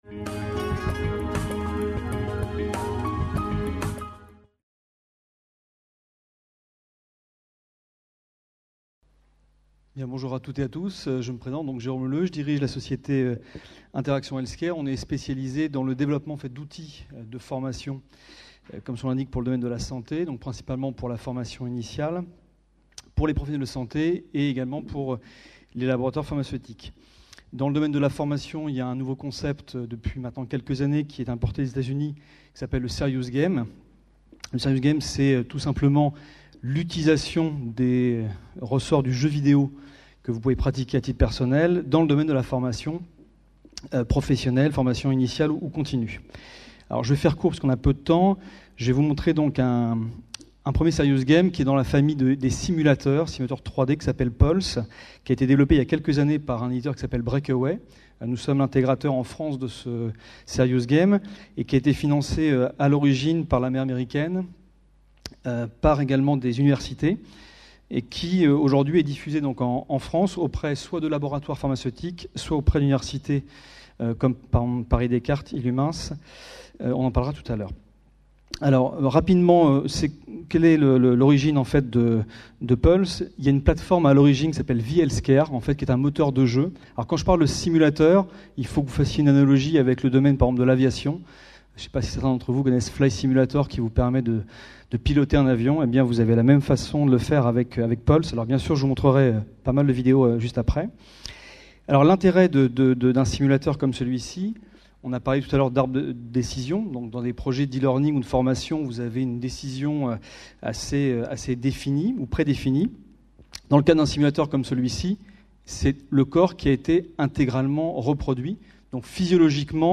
FORMATIC - Paris 2011 : PULSE, un «serious game» pour la formation des médecins et infirmiers. Conférence enregistrée lors du congrès international FORMATIC PARIS 2011. Atelier TIC et pratiques innovantes au service de la formation des professionnels de la santé.